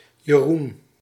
PronunciationDutch: [jəˈrun]